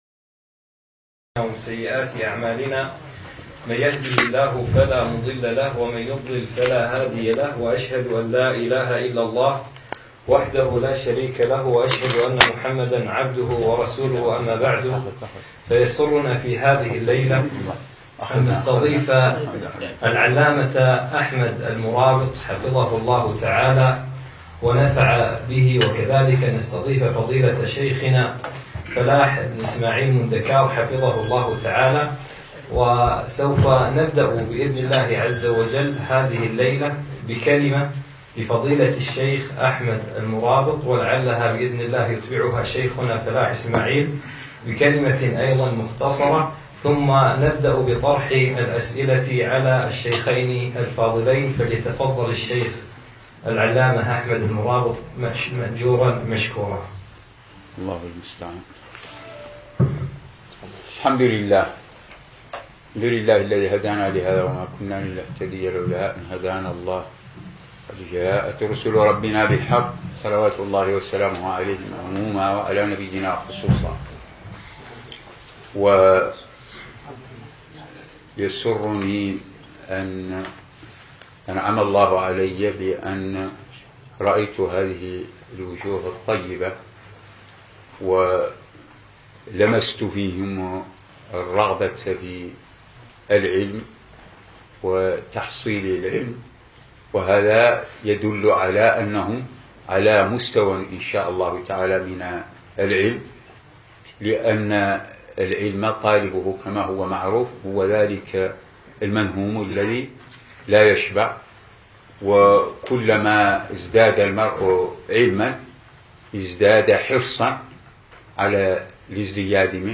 أقيمت المحاضرة في ديوان مشروع الدين الخالص